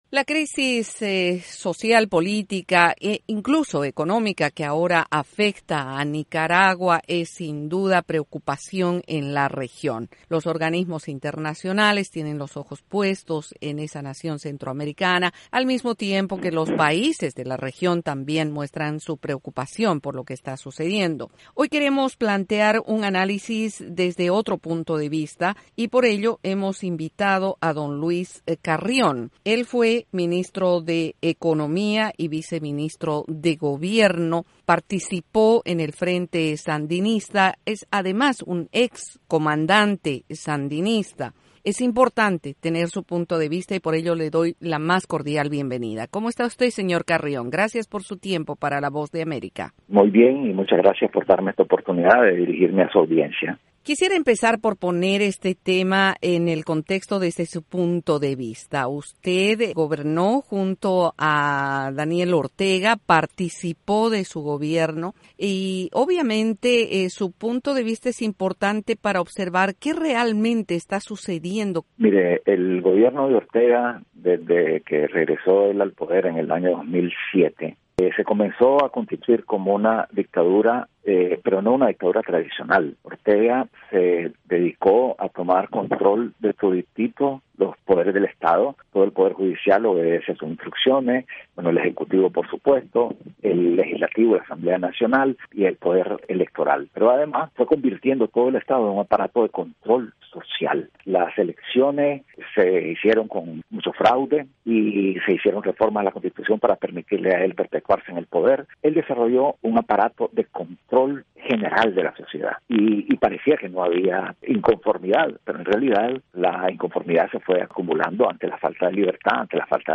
El ex comandante sandinista Luis Carrión dialoga sobre la situación en Nicaragua